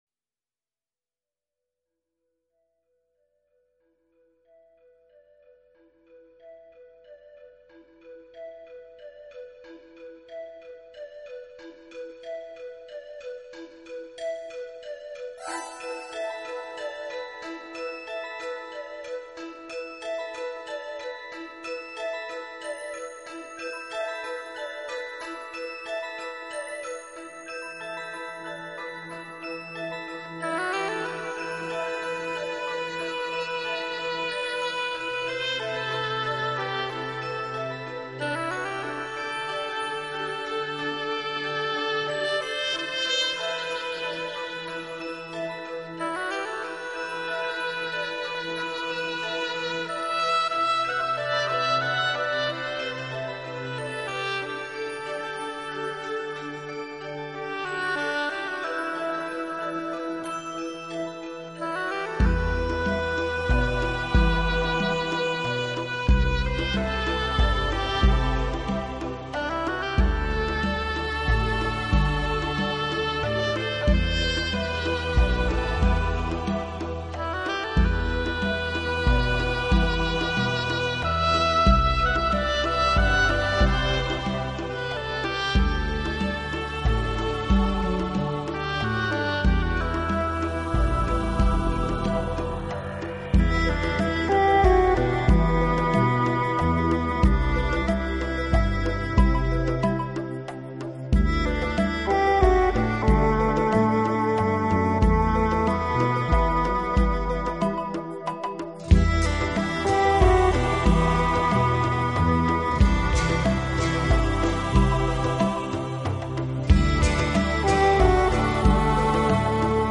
【新世纪纯音乐】